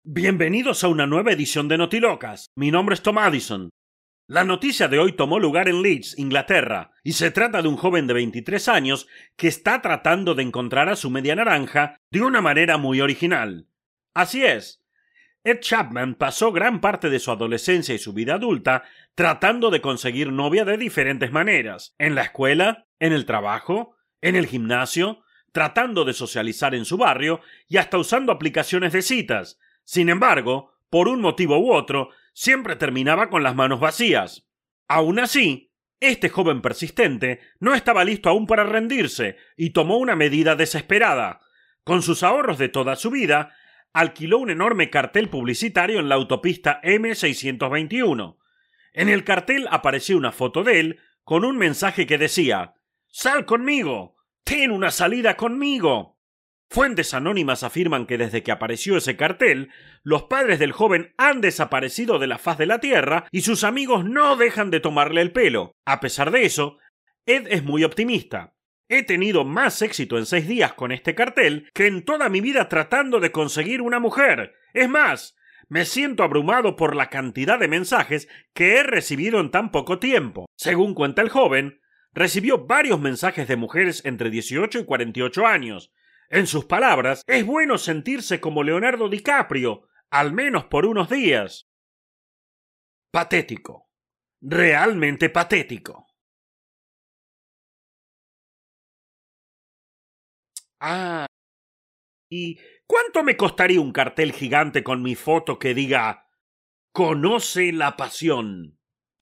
ESPAÑOL DE ARGENTINA